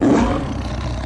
Car Tiger Engine Roar Sweetener